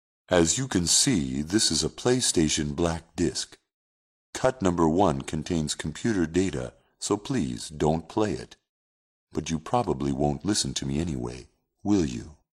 Μόλις τέλειωνε το μήνυμα, θα έπαιζε ένα τραγούδι του παιχνιδιού για να σε βάλει να το βγάλεις με αρκετό χρόνο.